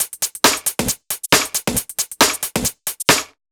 Index of /musicradar/uk-garage-samples/136bpm Lines n Loops/Beats